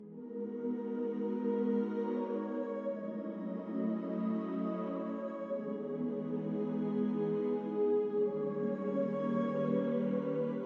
忧郁的合唱团
Tag: 90 bpm Orchestral Loops Choir Loops 1.79 MB wav Key : E FL Studio